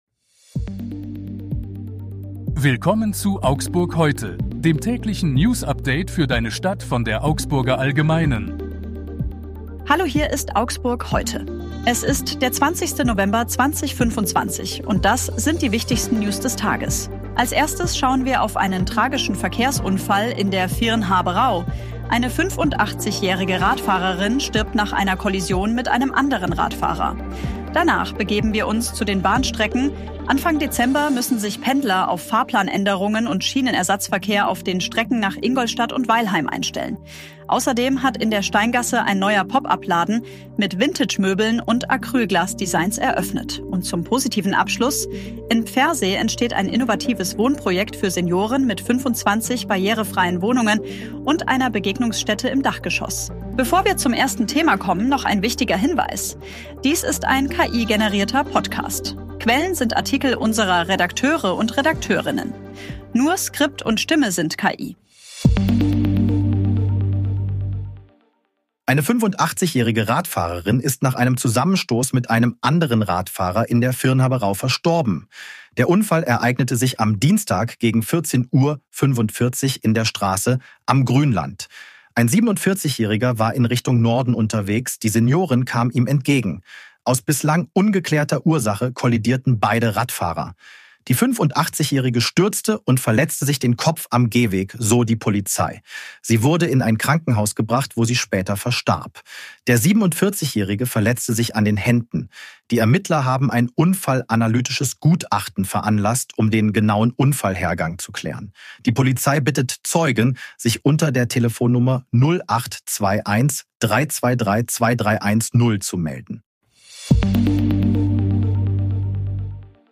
Hier ist das tägliche Newsupdate für deine Stadt.
Nur Skript und Stimme sind KI.